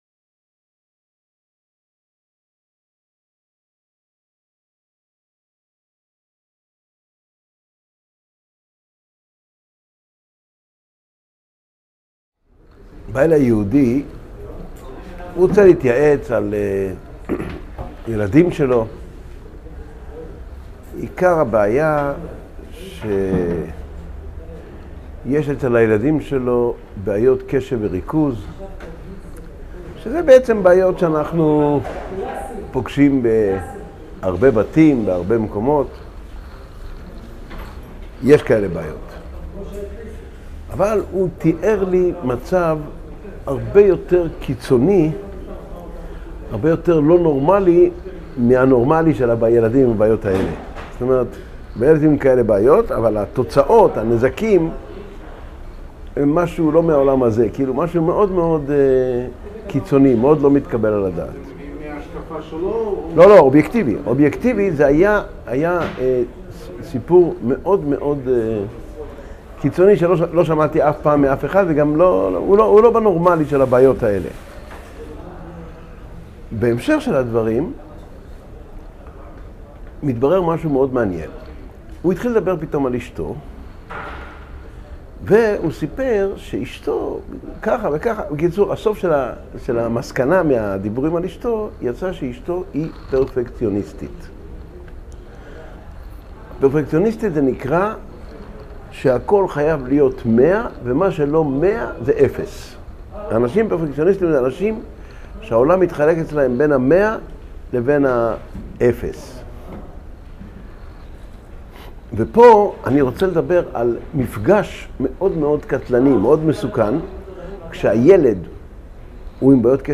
Урок № 75